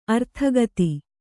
♪ arthagati